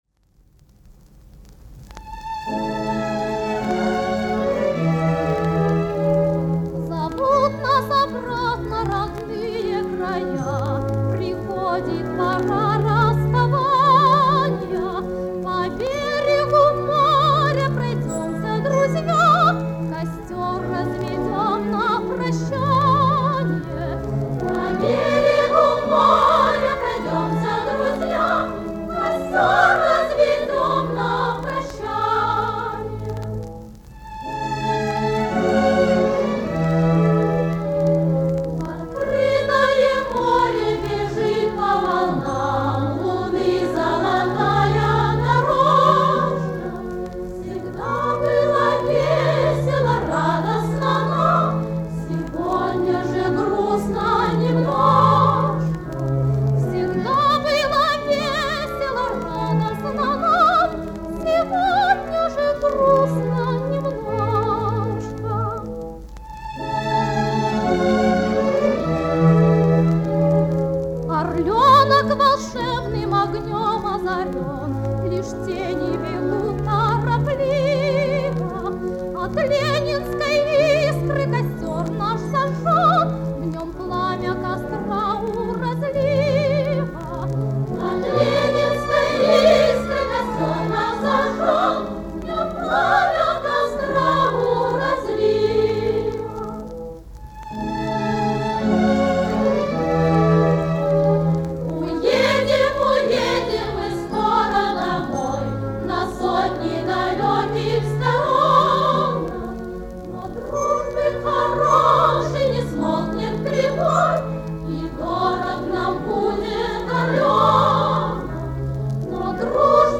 женский вок. анс.